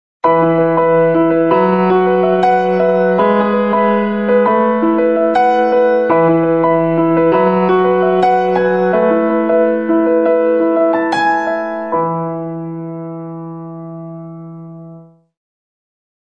Tags: best ringtones free soundboard